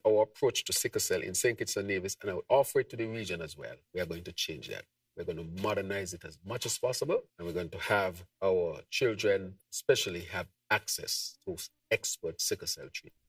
Prime Minister and Minister of Health, Hon. Dr. Terrance Drew.
During his Roundtable discussion on April 9th, Dr. Drew spoke on plans to transform the availability of sickle cell treatment in the federation with the help of a specialist in the field of Hematology out of Germany.